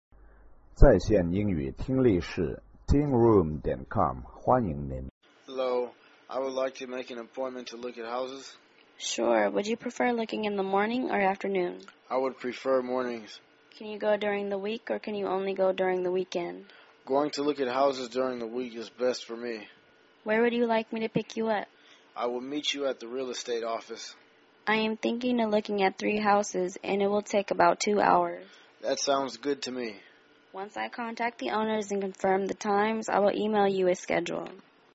英语情景对话-View Houses(1) 听力文件下载—在线英语听力室